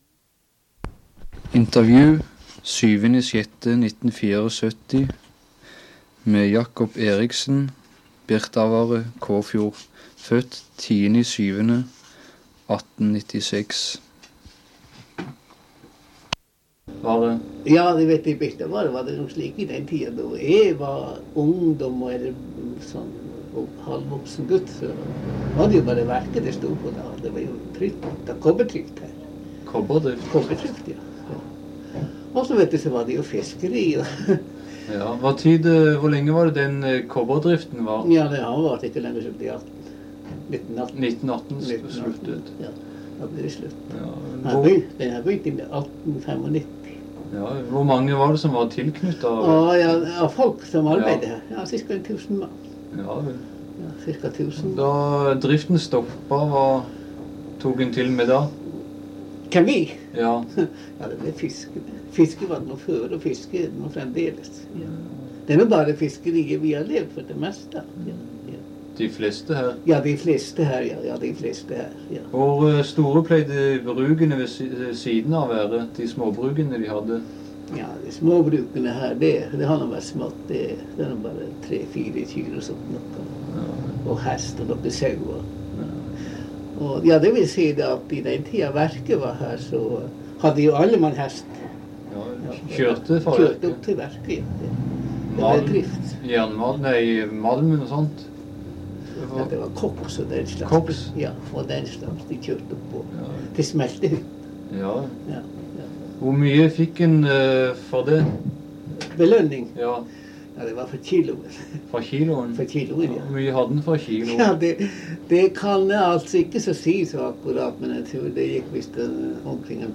U: Lydopptak